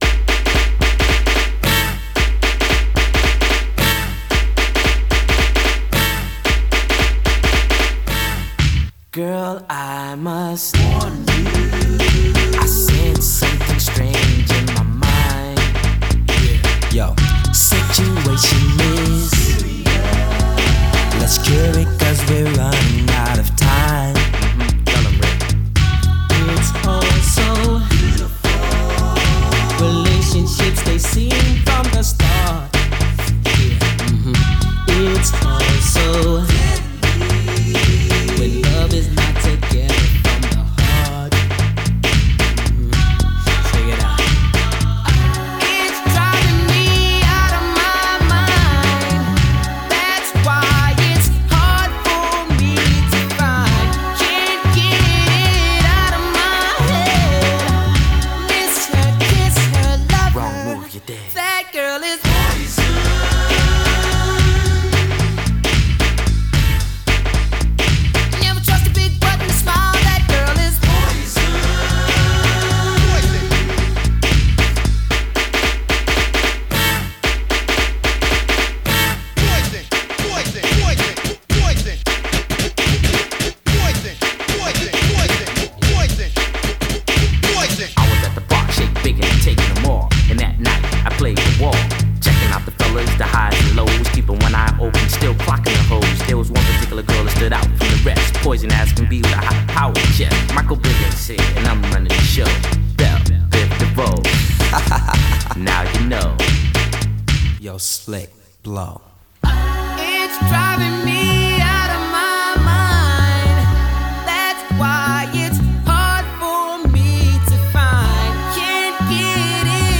BPM112
MP3 QualityMusic Cut